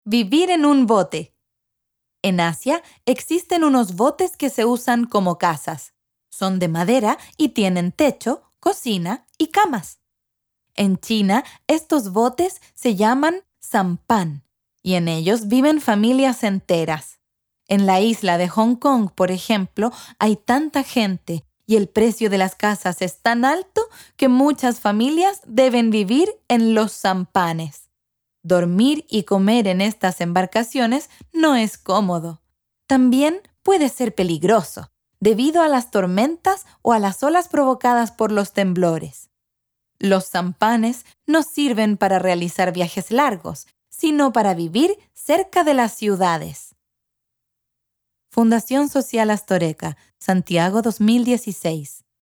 Audio informativo que narra cómo es vivir en un bote, una experiencia única. Vivir en un bote implica adaptarse a un espacio reducido, donde cada día es una nueva aventura.
Videos y Audiocuentos